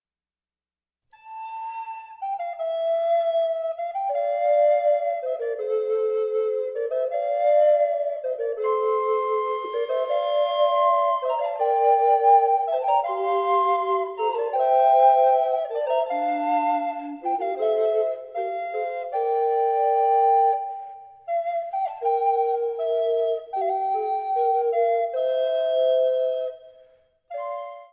An easy fantasy